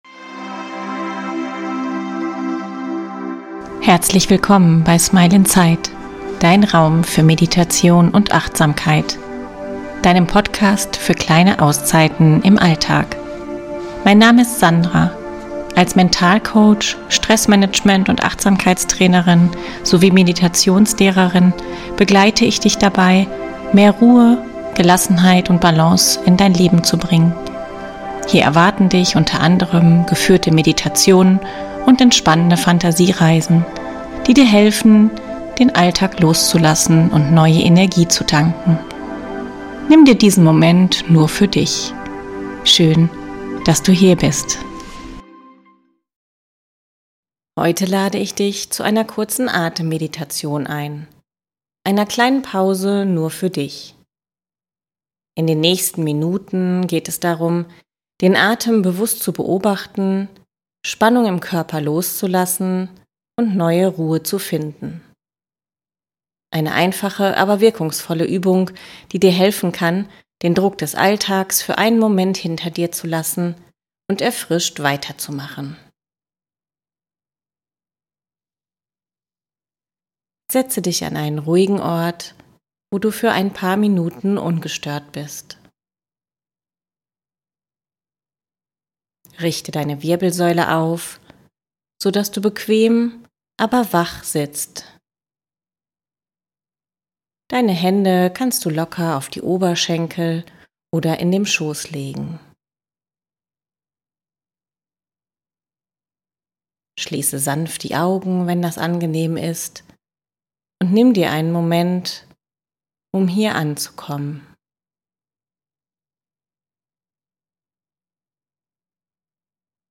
In dieser Folge lade ich dich zu einer 5–10-minütigen Atemmeditation ein. Wir richten die Aufmerksamkeit ganz auf den Atem, spüren das Heben und Senken von Bauch und Brustkorb und lassen bewusst Spannungen aus Gesicht, Schultern und Nacken los.